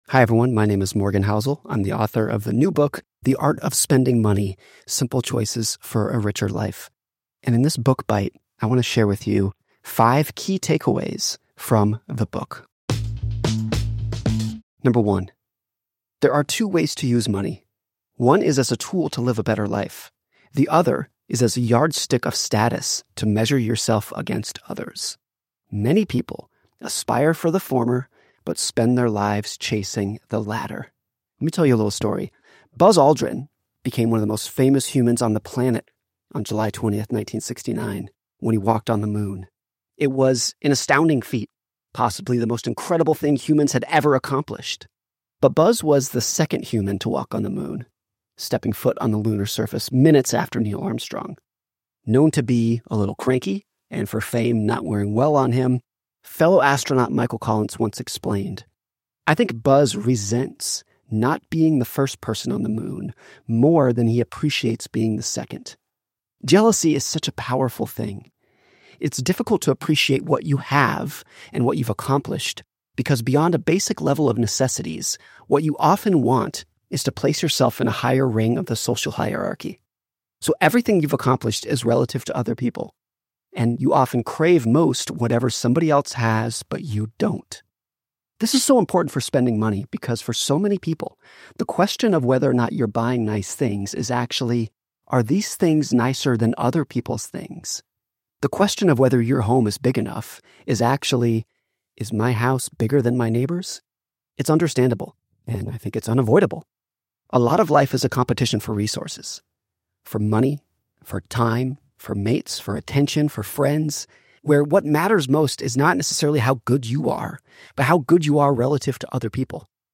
Listen to the audio version of this Book Bite—read by Morgan himself—below, or in the Next Big Idea App.